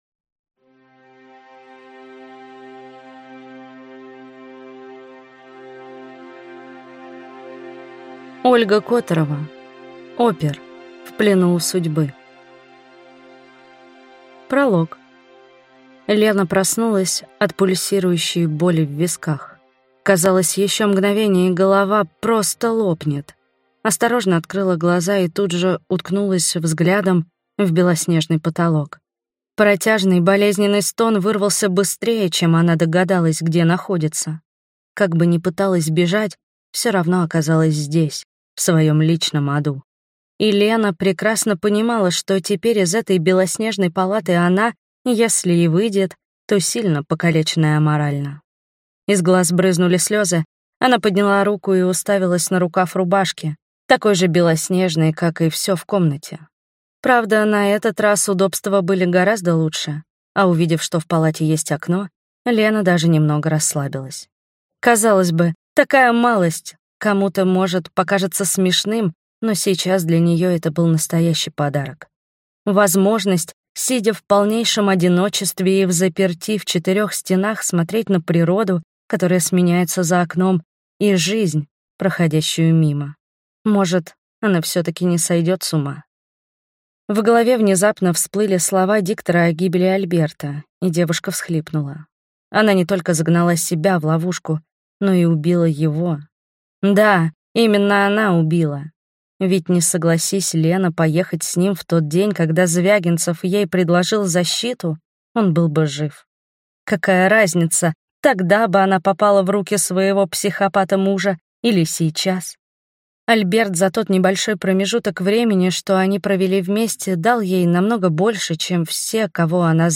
Аудиокнига Опер. В плену у судьбы | Библиотека аудиокниг